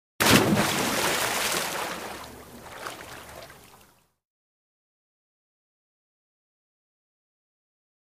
Large Splash Or Dive Into Water 2.